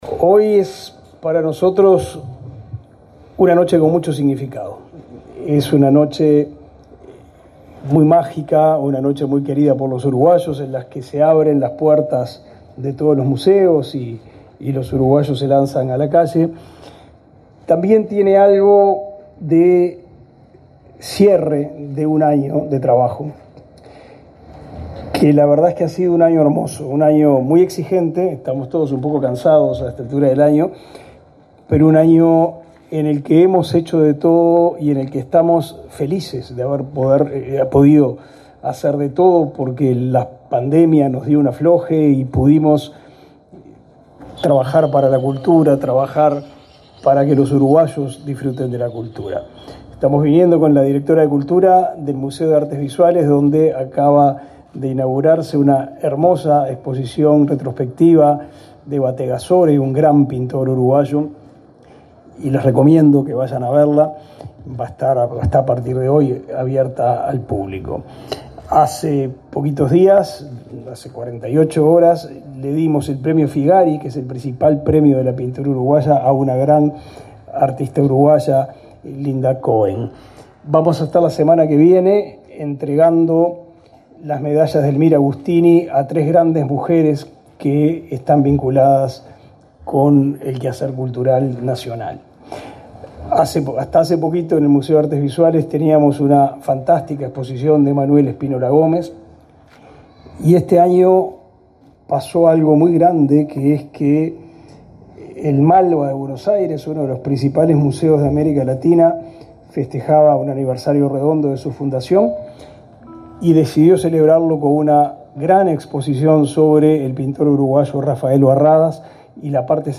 Palabras del ministro Pablo da Silveira